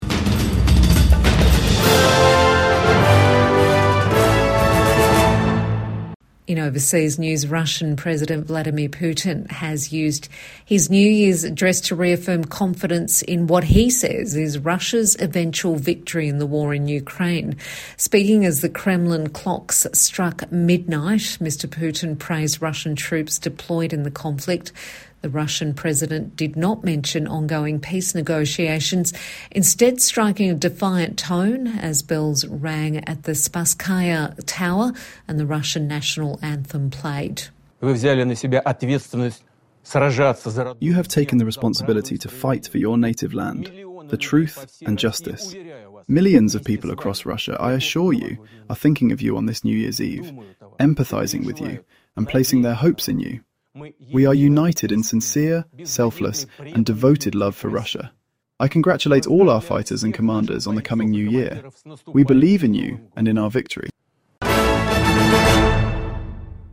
Vladimir Putin delivers New Year's address